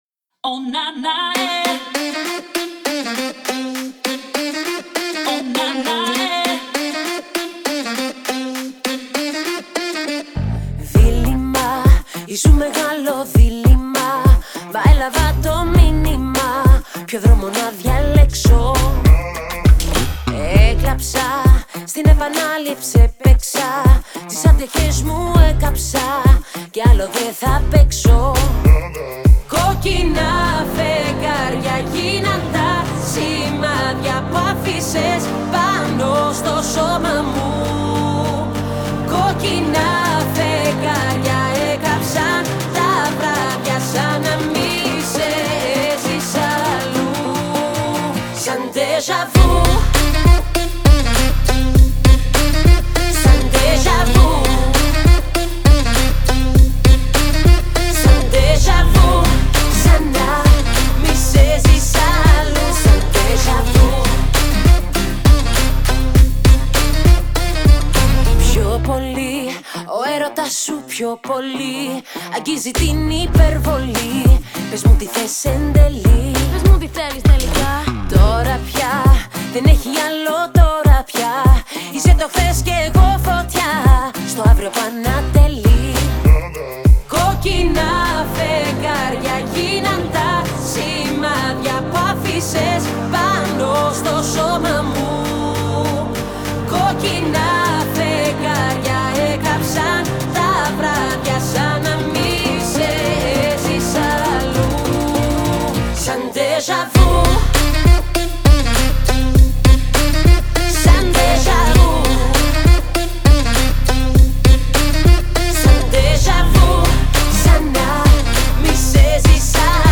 это яркая поп-песня